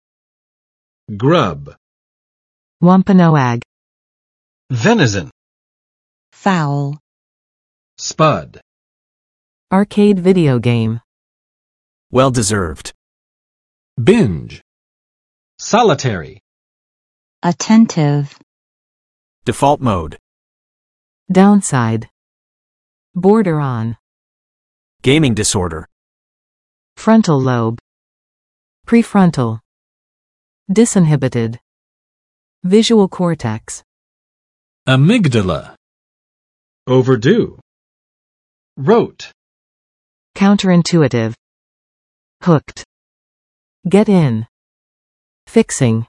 grub [grʌb] n. Slang. food